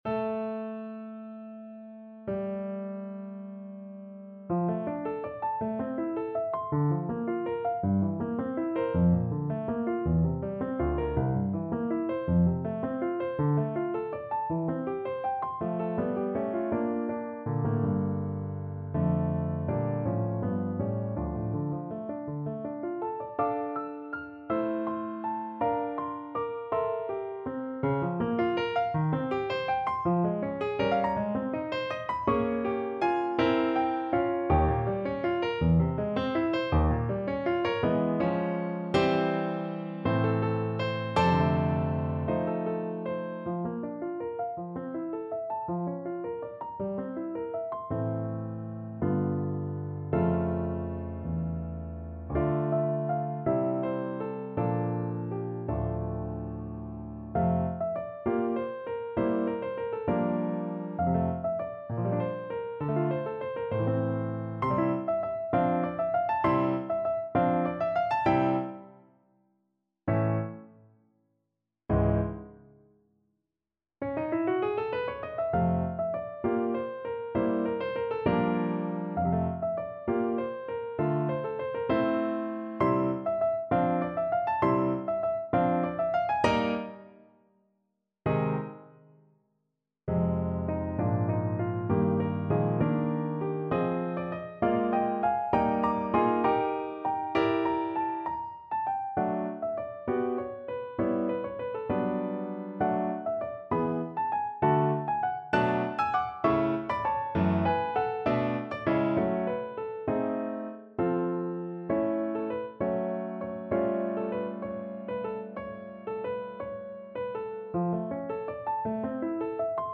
Classical (View more Classical Flute Music)